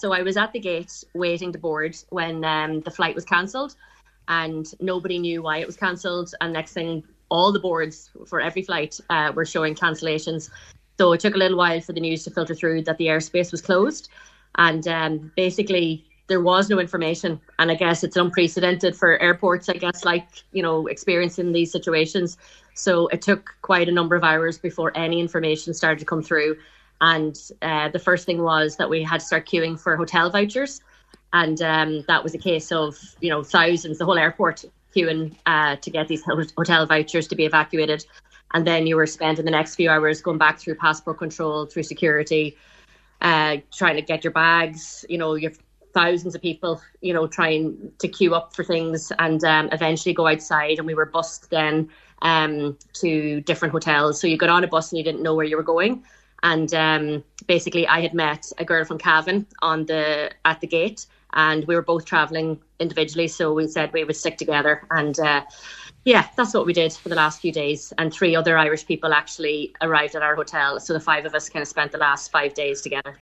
Donegal woman describes being stranded in Dubai